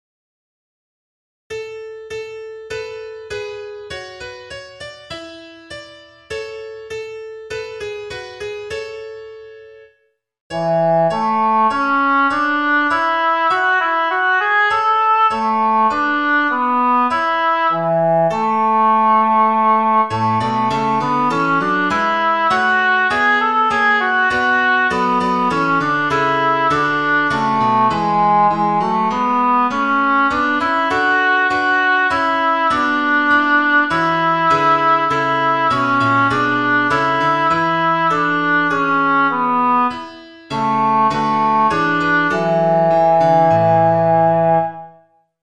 Nu-sijt-willekome-2-tenor.mp3